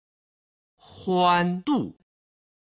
今日の振り返り！中国語発声
欢度　(huān dù)　楽しく過ごす